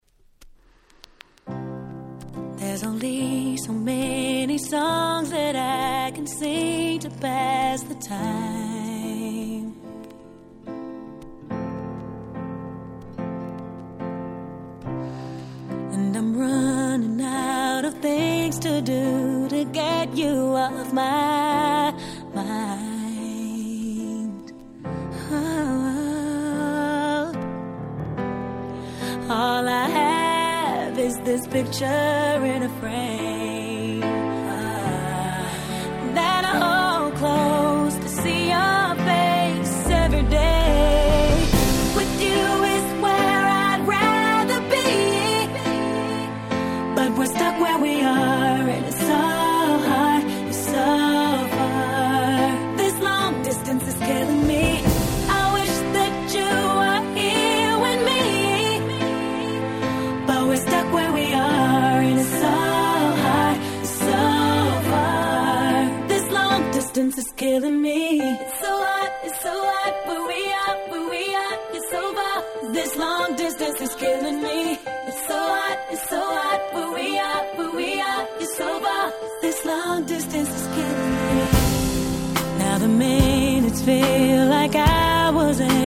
08' Super Hit R&B !!